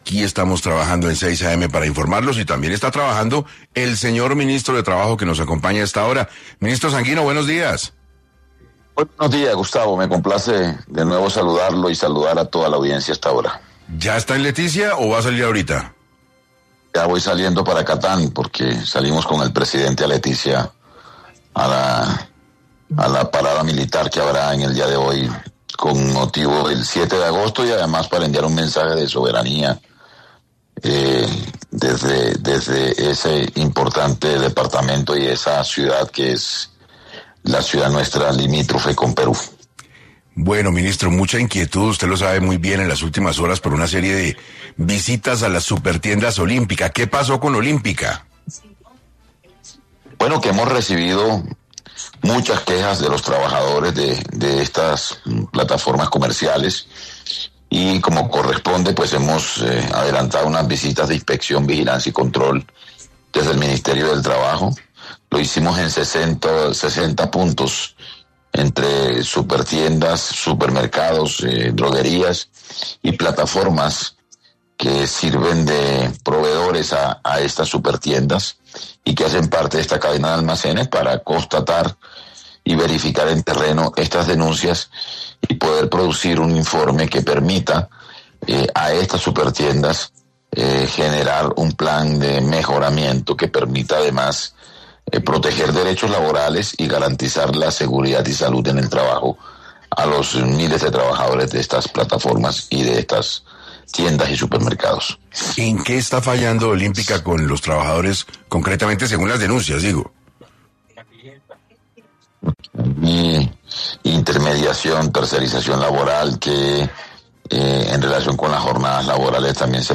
El ministro de Trabajo, Antonio Sanguino, estuvo en 6AM para abordar la inspección de tiendas y droguerías Olímpica, tras denuncias de los trabajadores.